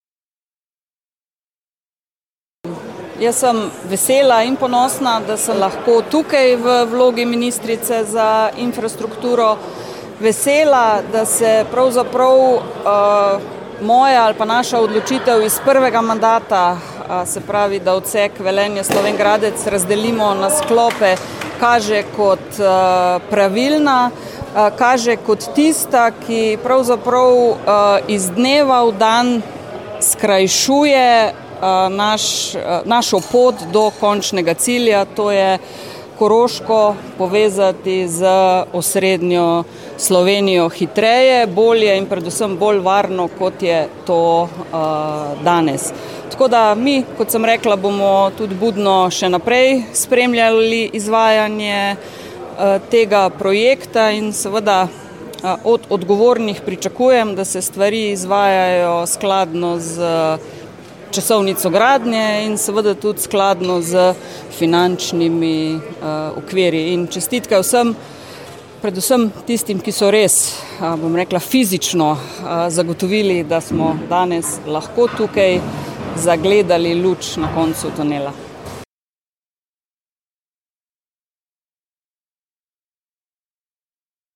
Ministrica za infrastrukturo Alenka Bratušek je spomnila, da je bila odločitev za gradnjo po odsekih pravilna: